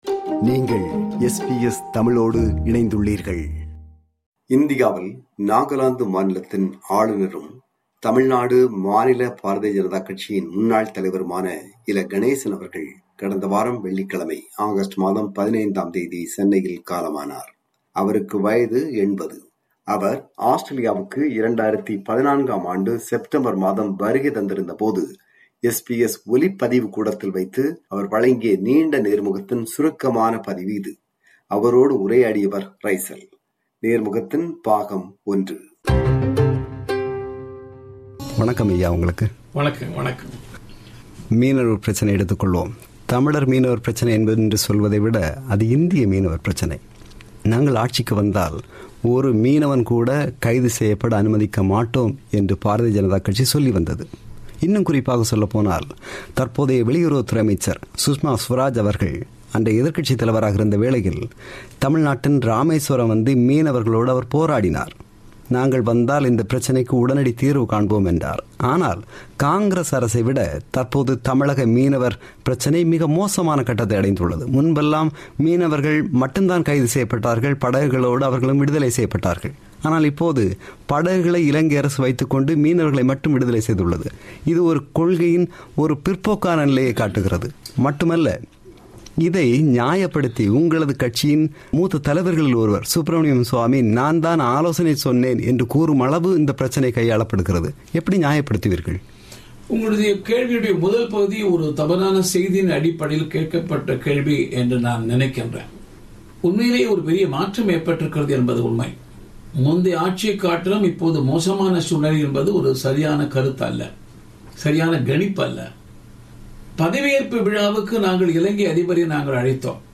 அவர் ஆஸ்திரேலியாவுக்கு 2014 செப்டம்பர் மாதம் வருகை தந்திருந்தபோது SBS ஒலிப்பதிவு கூடத்தில் வைத்து வழங்கிய நீண்ட நேர்முகத்தின் சுருக்கமான பதிவு.
நேர்முகம் பாகம் 1.